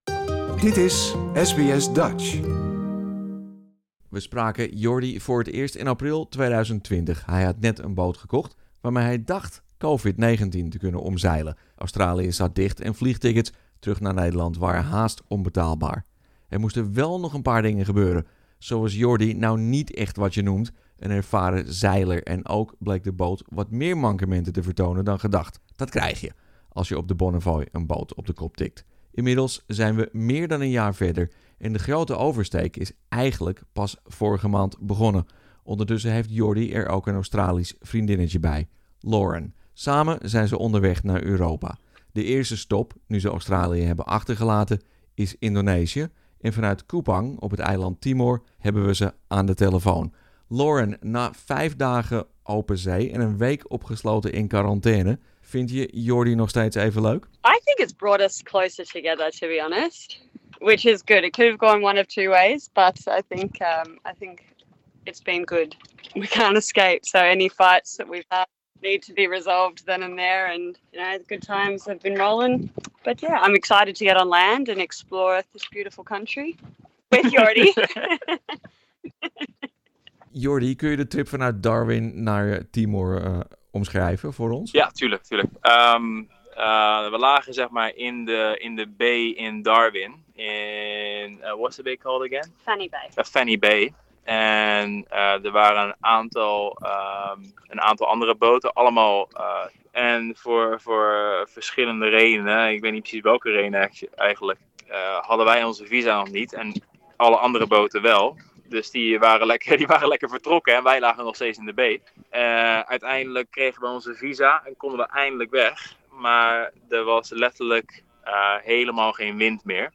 belde ze op in Kupang, op het eiland Timor.